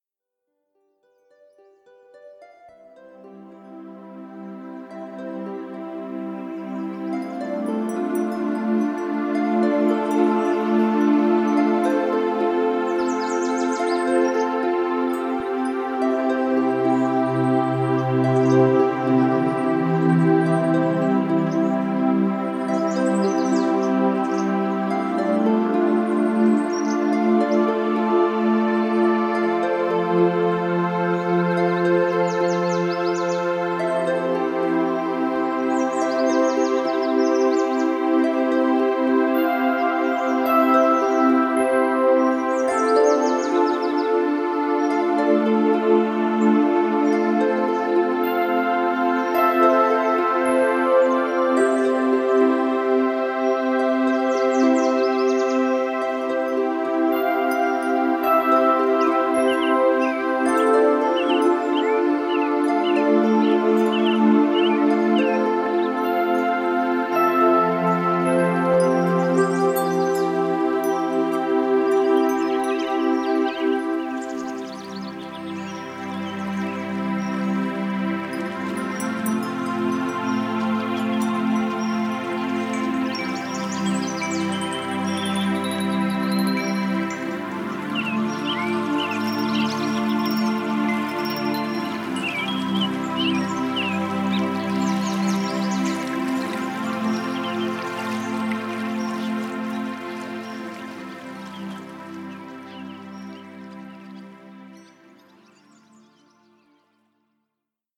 ca. 00:31:16 Minuten (Version mit Naturgeräuschen)